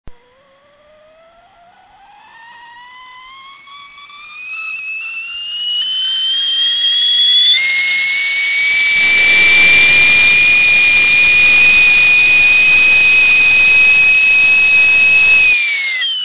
Звуки кипящего чайника
Свист закипающего чайника